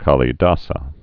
(kälē-däsə) AD 375?-415?